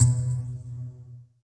SYN_Pizz1     -R.wav